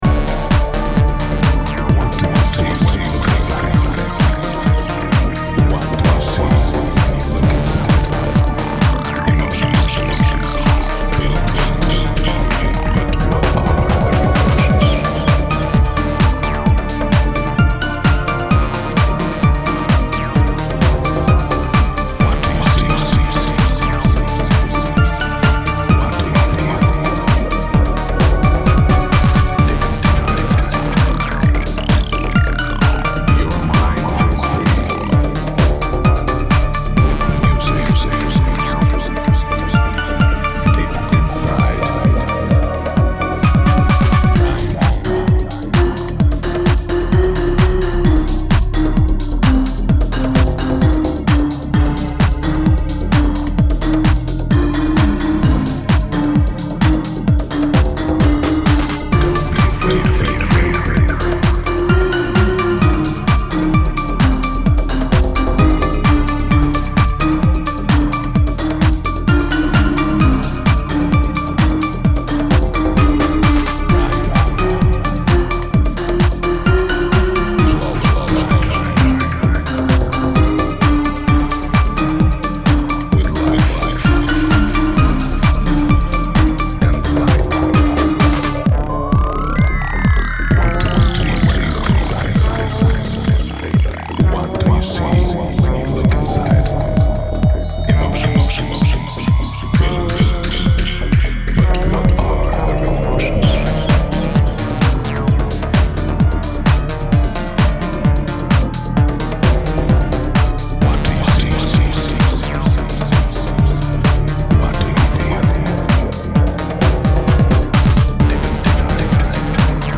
dark flavour oozing throughout the EP